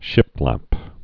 (shĭplăp)